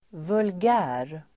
vulgär adjektiv, vulgar Uttal: [vulg'ä:r] Böjningar: vulgärt, vulgära Synonymer: grov, snuskig Definition: okultiverad; smaklös (common) Exempel: ett vulgärt skratt (a vulgar laugh) Sammansättningar: vulgär|propaganda (vulgar propaganda)